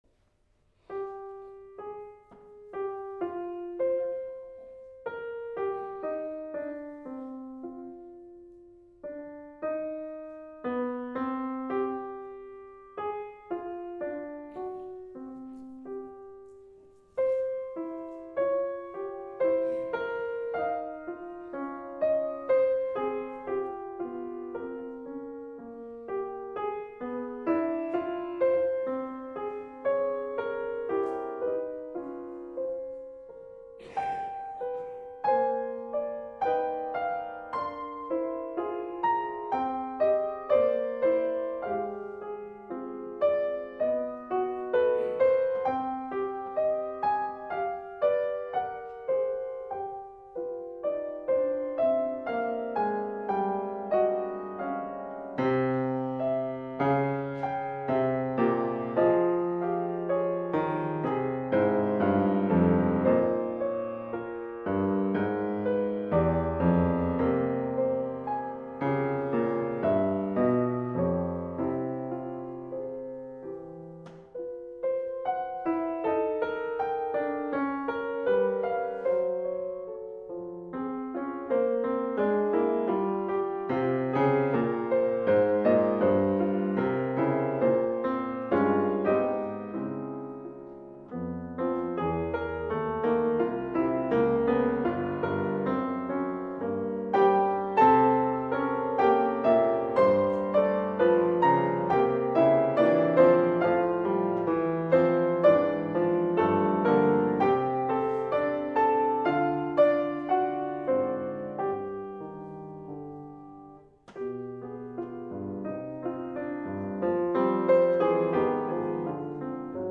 Instrumentation: piano solo
piano.
Ultan Recital Hall, University of Minnesota.